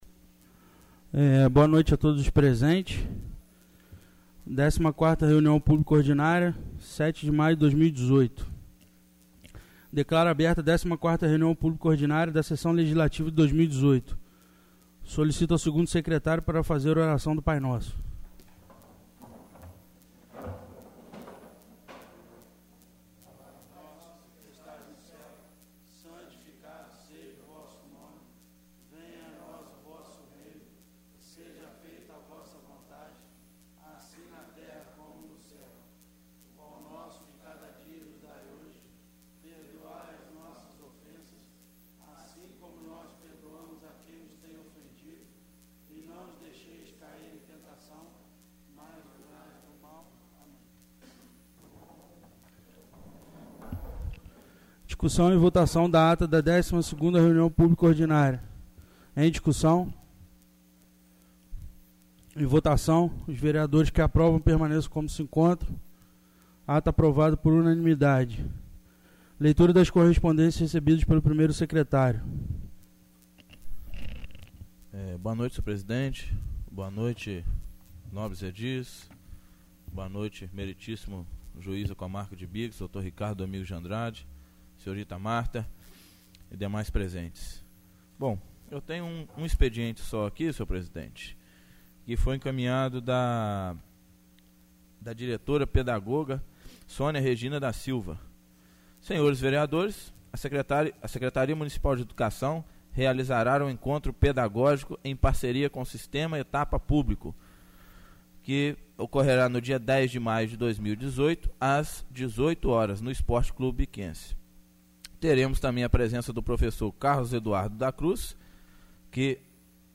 14º Reuniao Pública Ordinária 07/05/2018